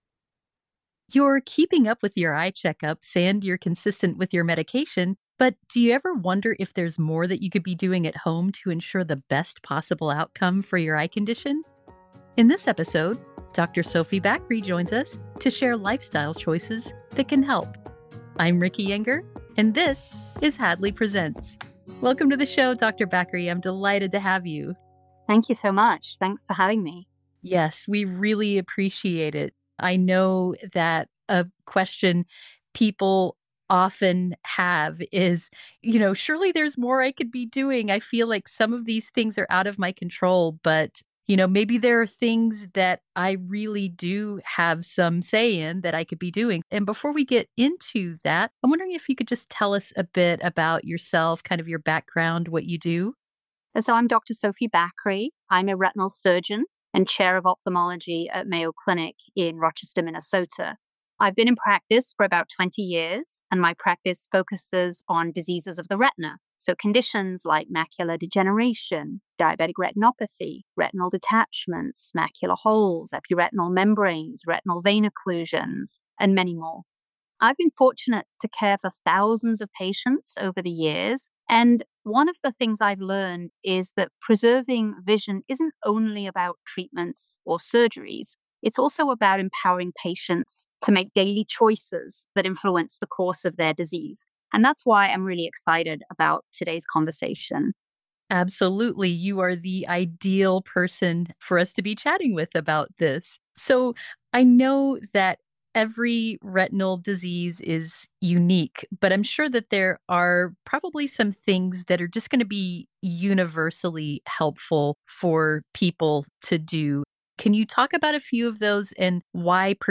An episode of the Hadley Presents: A Conversation with the Experts audio podcast